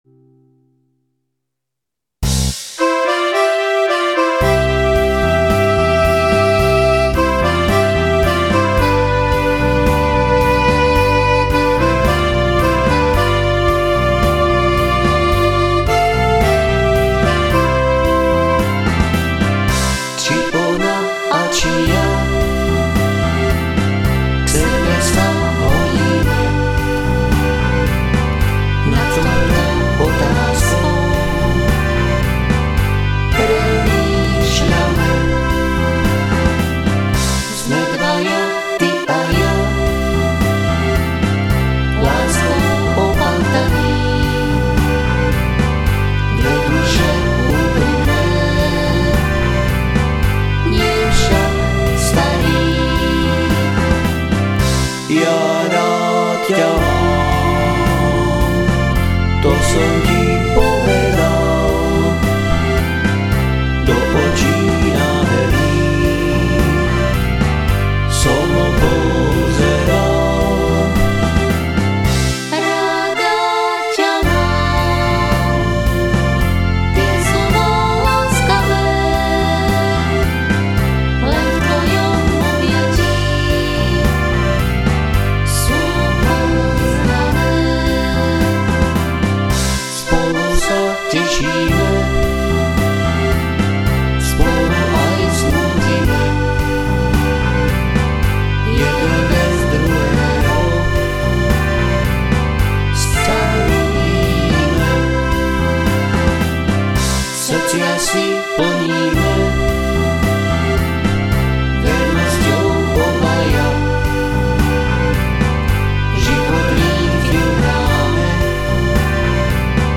CD3 - tango o nás dvoch ?